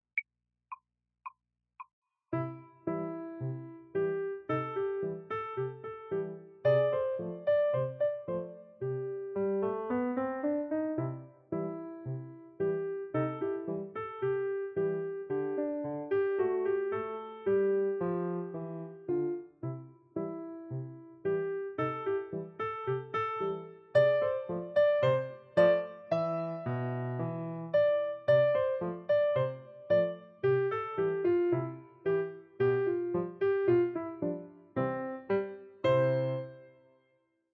for easy piano